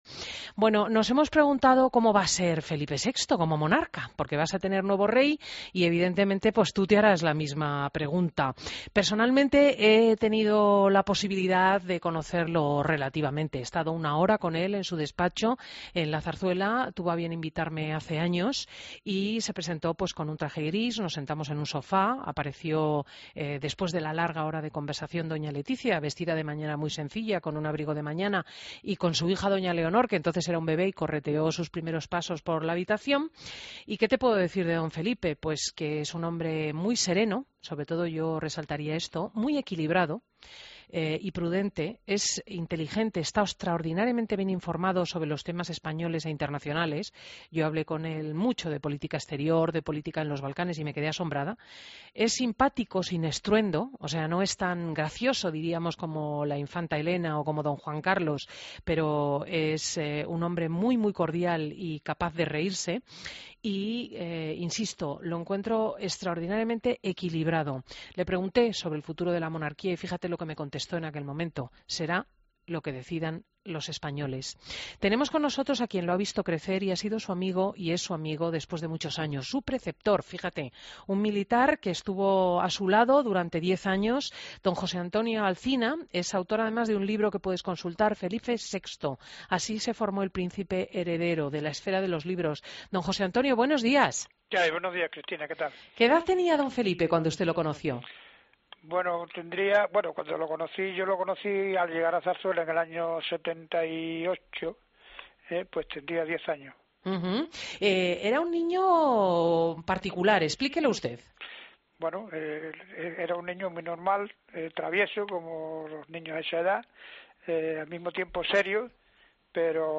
Entrevistas en Fin de Semana Entrevista